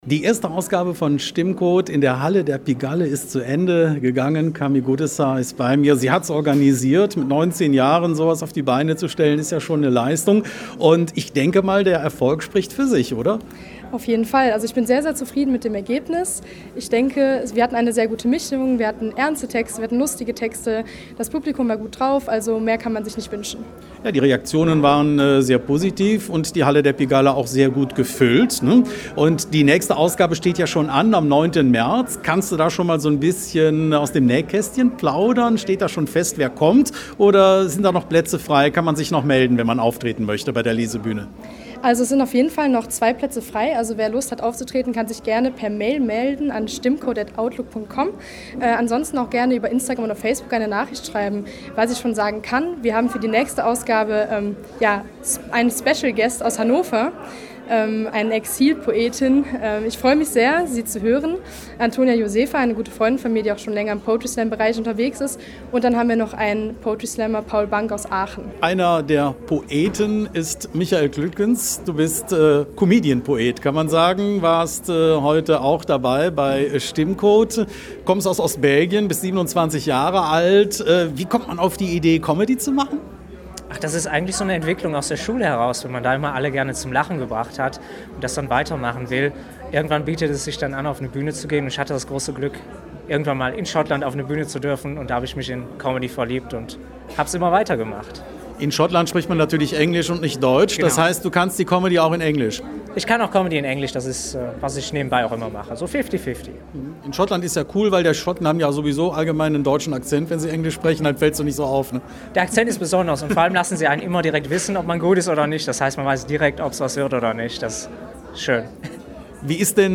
Am Mittwochabend (02.02.2022) feierte in der Halle der Pigalle in Eupen „StimmCode“ premiere. Die Lesebühne bietet Poetinnen und Poeten aus Ostbelgien und darüber hinaus eine Möglichkeit vor Publikum aufzutreten.